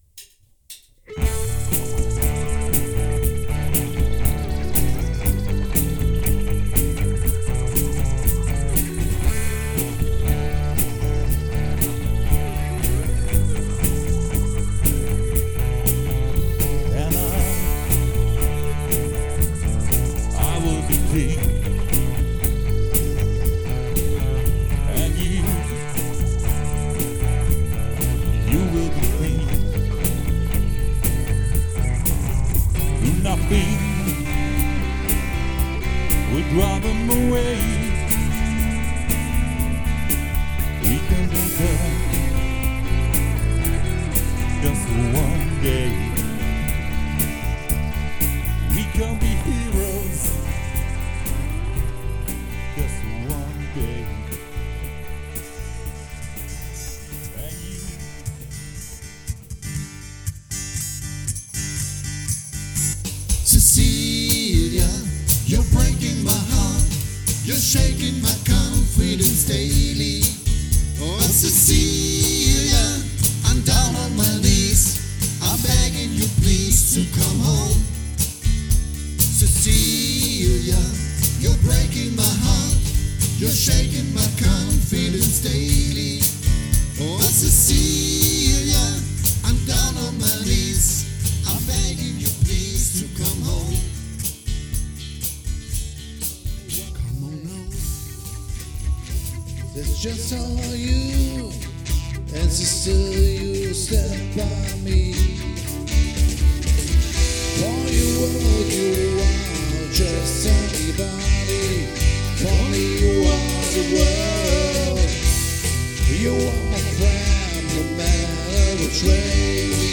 Maddley eigener Songs und folgender Coverversionen
Livemitschnitte